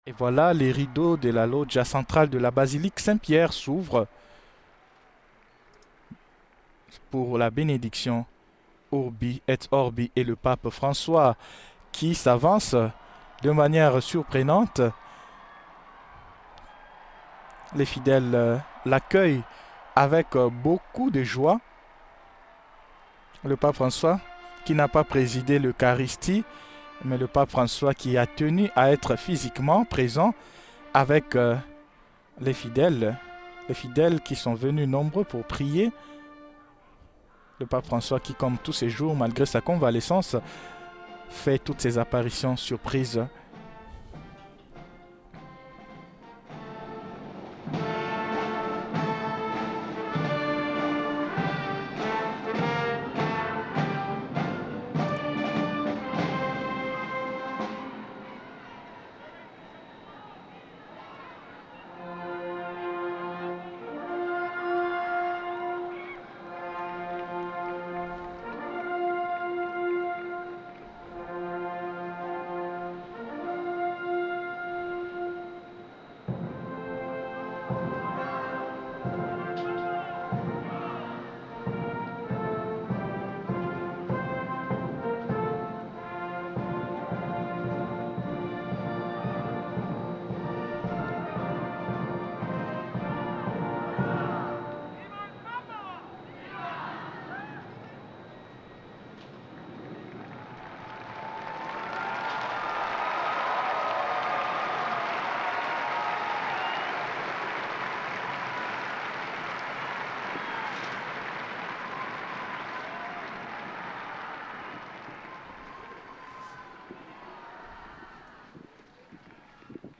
Depuis la place Saint-Pierre à Rome, le pape François adresse son message de Pâques Urbi et Orbi, "à la ville et au monde". Un moment d’Église universelle, de prière et d’espérance, où le Saint-Père proclame la joie de la Résurrection et appelle à la paix pour notre monde blessé.